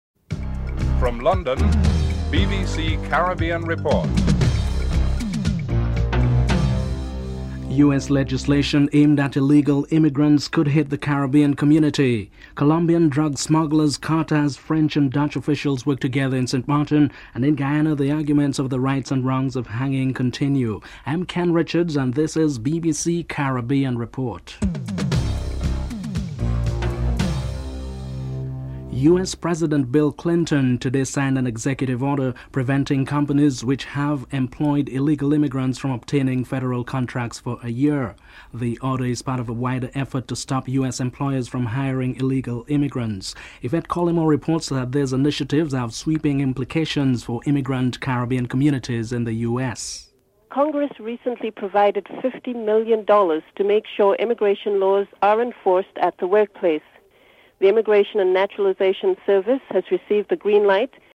1. Headlines (00:00-00:28)
Clive Lloyd, former West Indies captain and World Cup referee is interviewed (13:00-15:18)